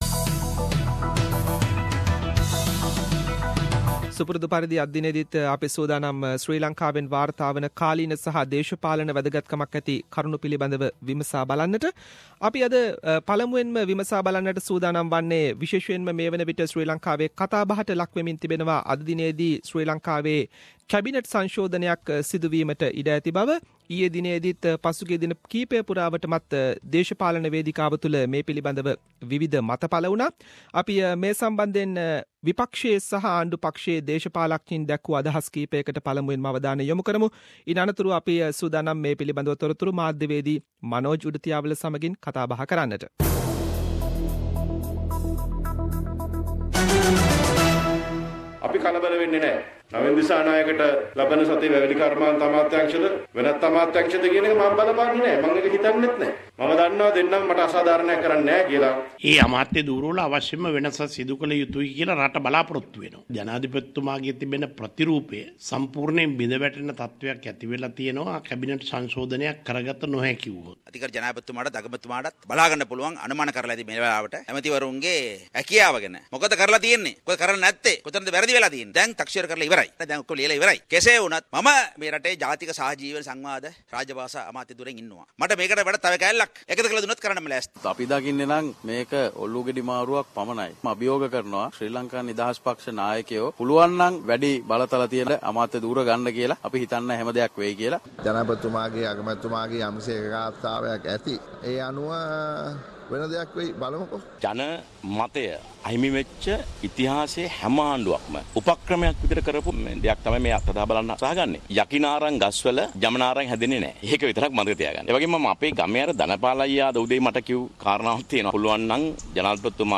Weekly Sri Lankan political highlights - The comprehensive wrap up of the highlighted political incidents in Sri Lanka…..Senior Journalist - News and current affair